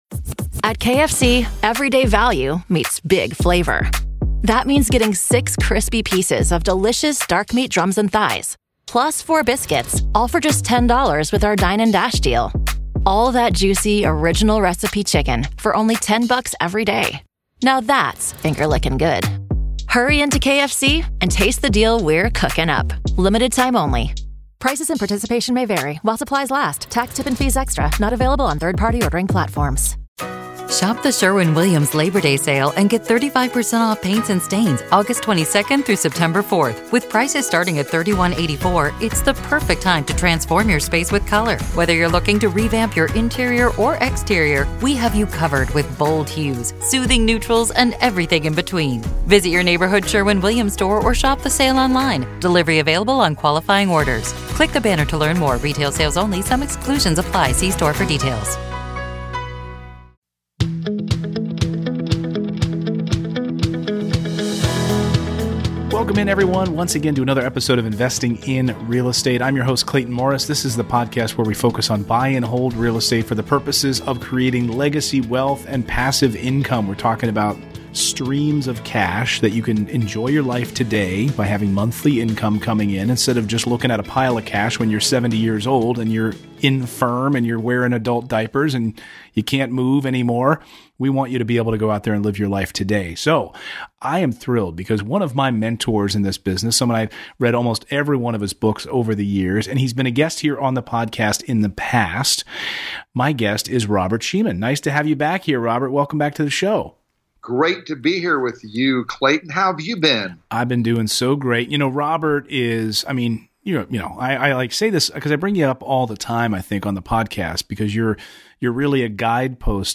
You CAN Make Money with Low Cost Rental Properties - Interview